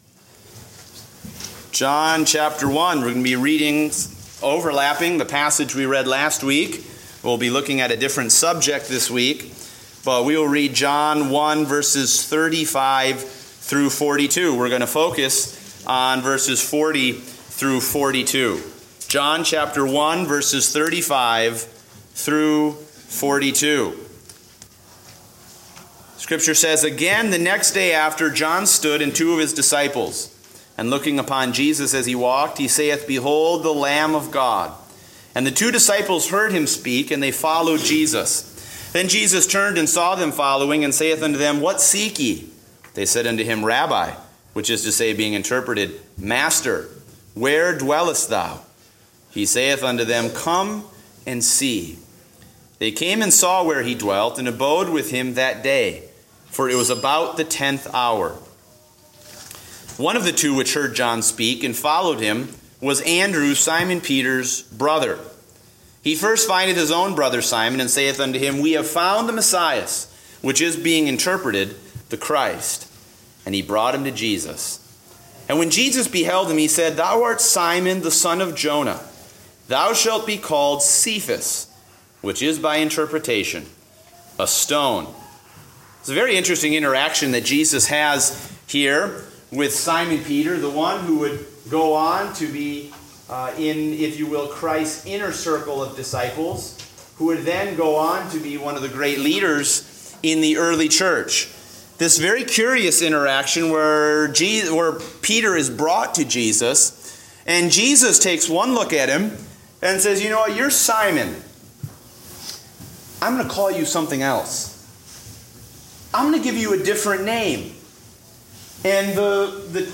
Date: April 24, 2016 (Adult Sunday School)